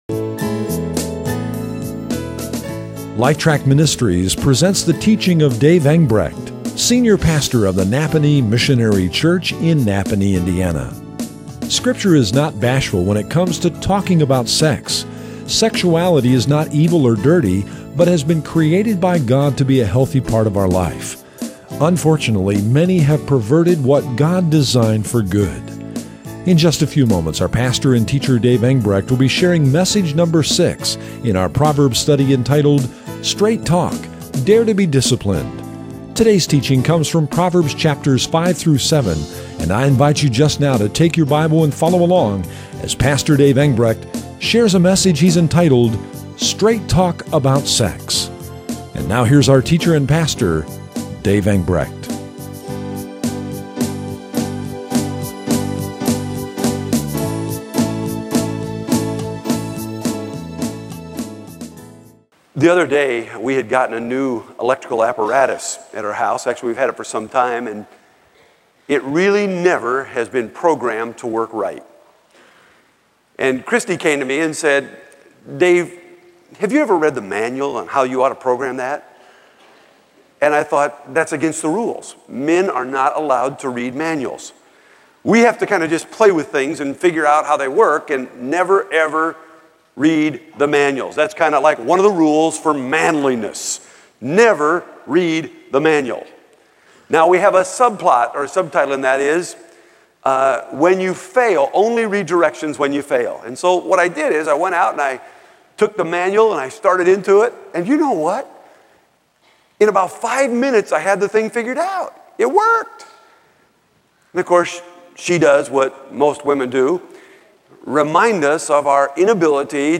Yes, my local pastor did (link to his message below)–but that was almost a decade ago–July 2005.